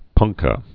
(pŭngkə)